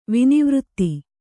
♪ vinivřtti